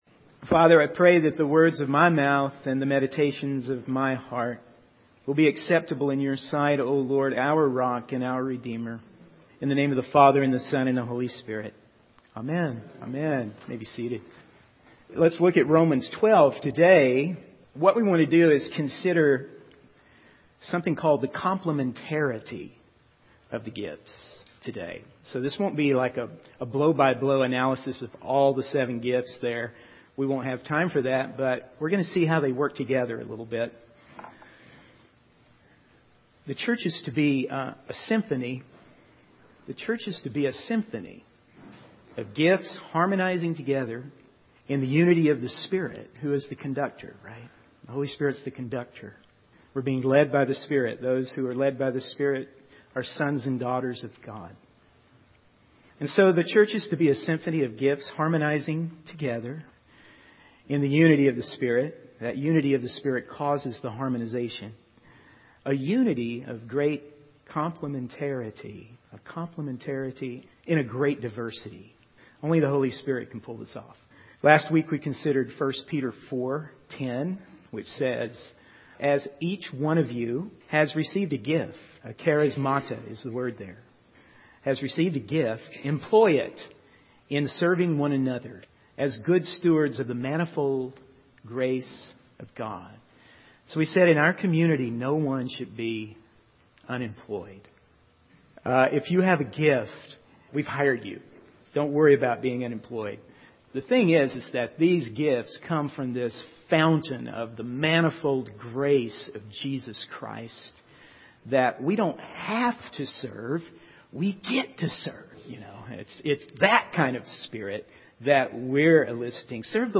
In this sermon, the speaker discusses the completion of a membership class and the ministry assessment that was taken.